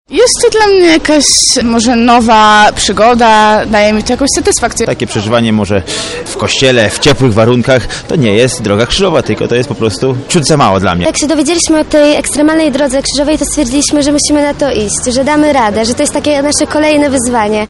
EDK-Ludzie.mp3